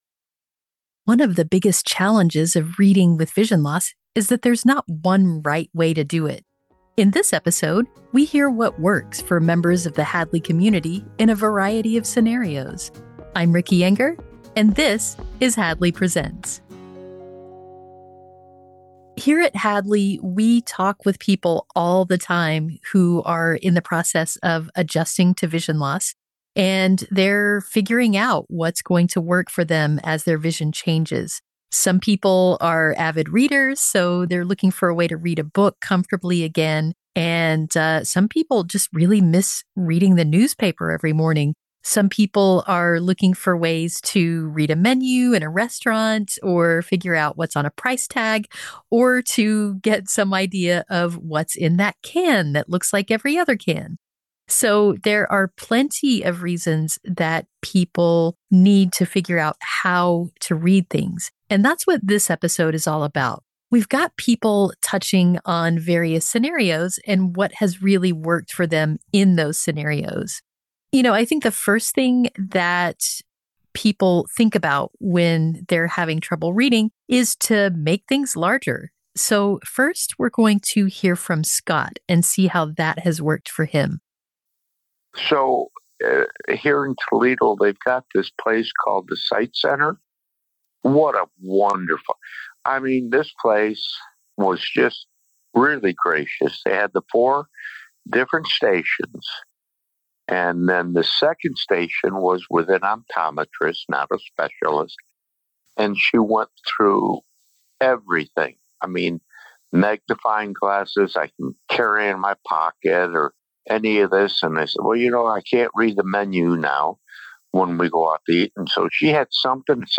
In this episode, members of the Hadley community share what's worked for them, from magnifiers and large print to audiobooks, braille and various apps.